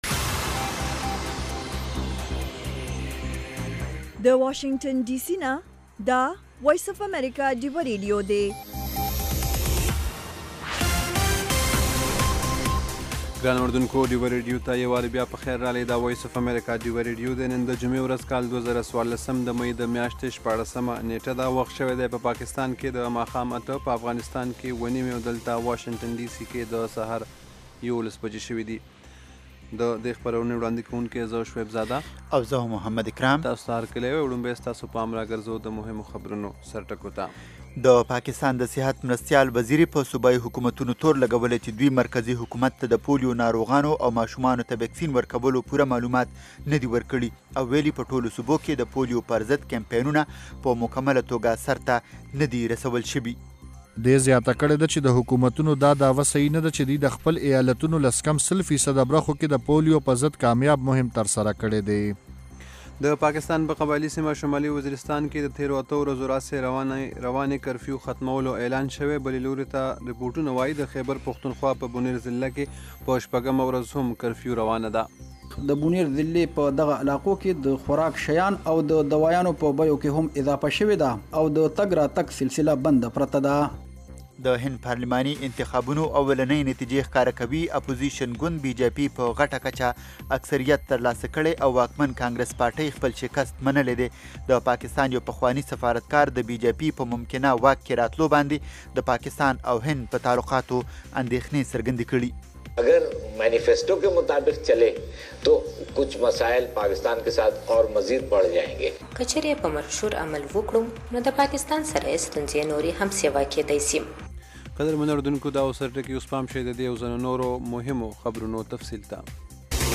خبرونه - 1500
د وی او اې ډيوه راډيو خبرونه چالان کړئ اؤ د ورځې د مهمو تازه خبرونو سرليکونه واورئ.